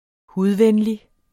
Udtale [ ˈhuðˌvεnli ]